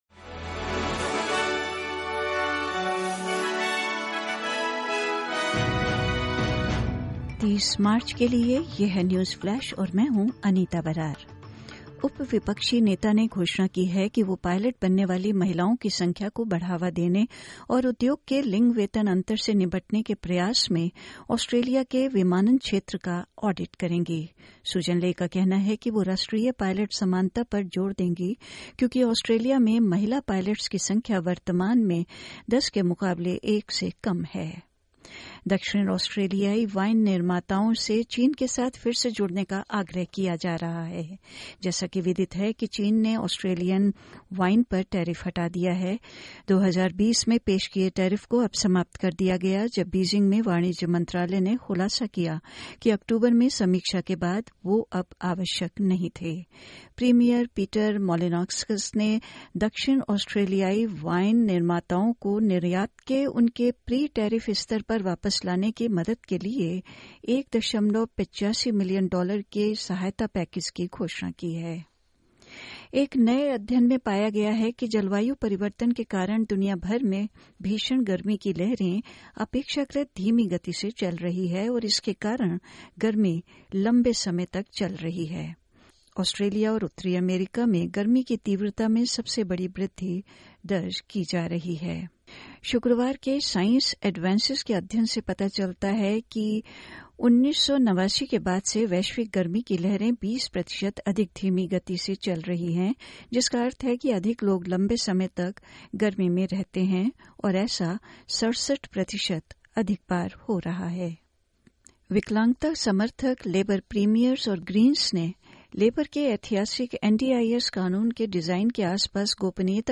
SBS Hindi Newsflash 30 March 2024: The South Australian Premier announces support package for winemakers